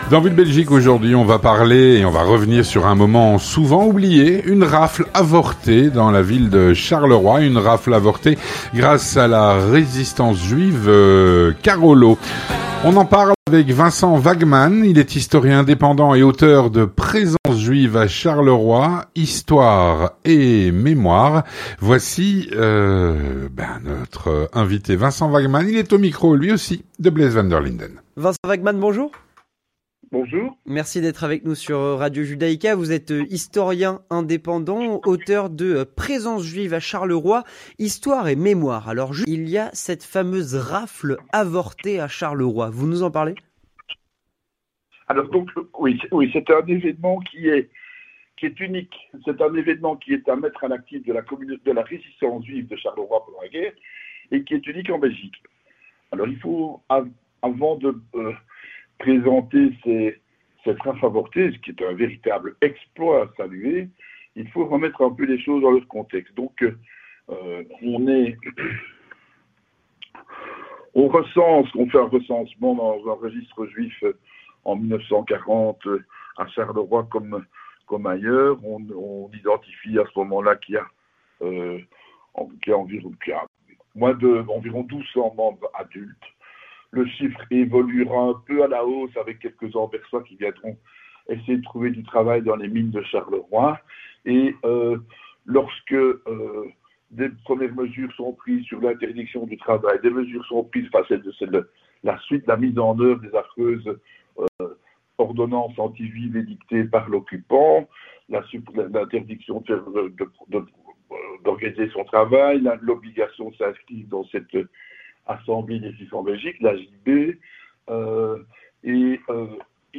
Avec notre invité aujourd’hui nous revenons sur un moment souvent oublié : une rafle avortée à Charleroi.